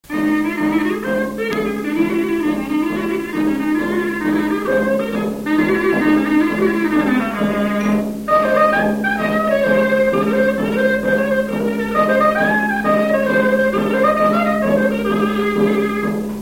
Avant-deux
Localisation Bazoges-en-Pareds
Résumé instrumental
danse : branle : avant-deux
Catégorie Pièce musicale inédite